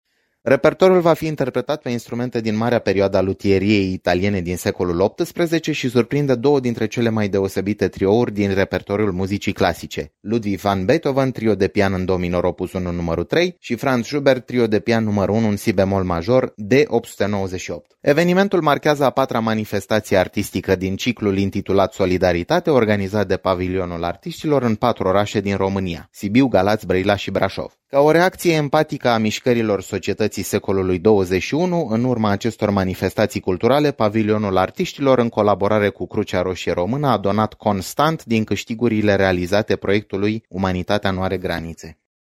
Mai mute detalii ne oferă violonistul